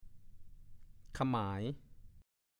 ฐานข้อมูลพจนานุกรมภาษาโคราช
1. (ขะมาย) ขโมย หรือลักเอาสิ่งของจากที่ถูกขโมยมาอีกต่อหนึ่ง